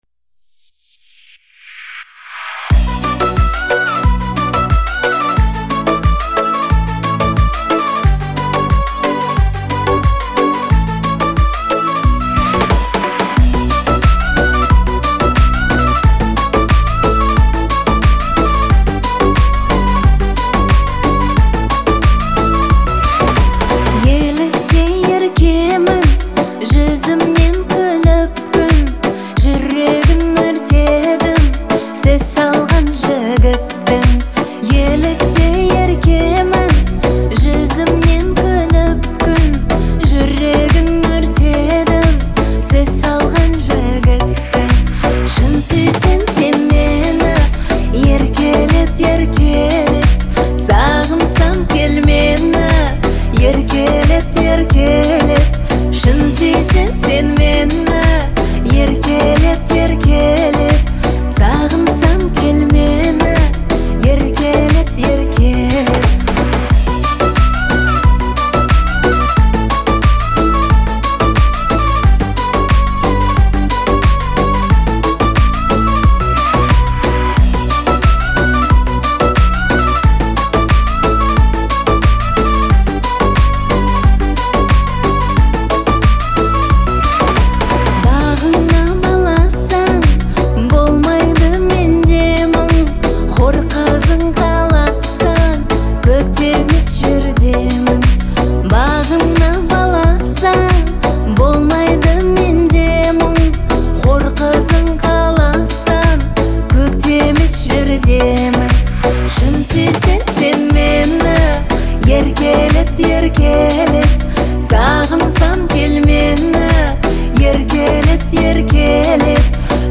отличается выразительным голосом и душевным исполнением